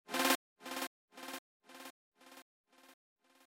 Как был создан этот Chord Stab?